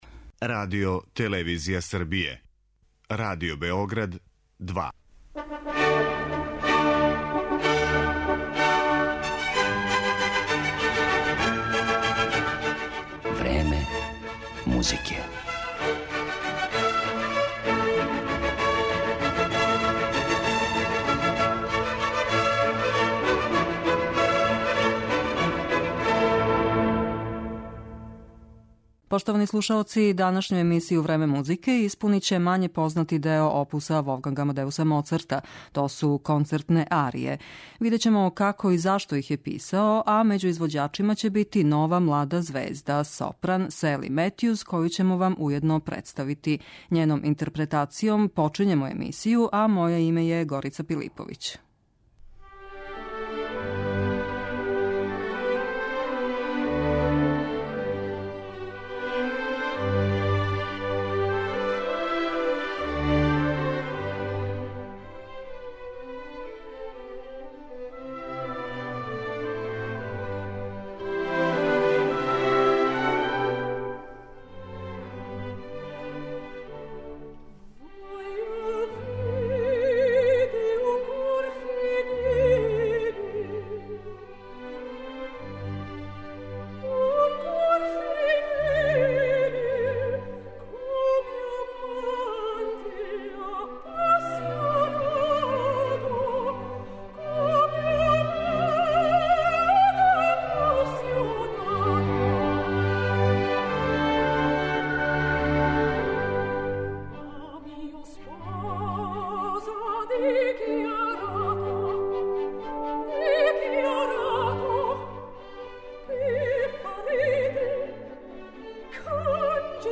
Данашњу емисију 'Време музике' испуниће мање познати део опуса Волфганга Амадеуса Моцарта - то су концертне арије.